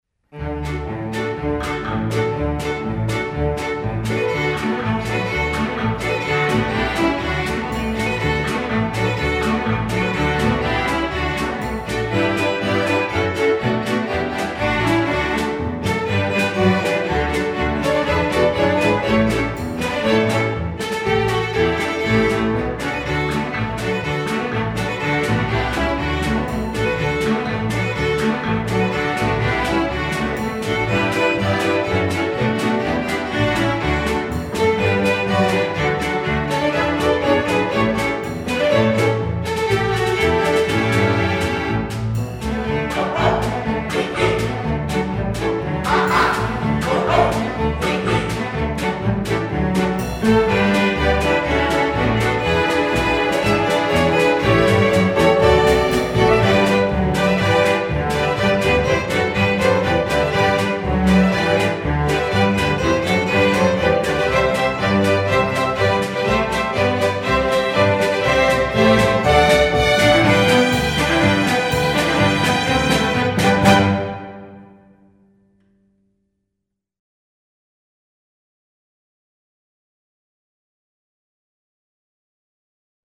Voicing: String Orc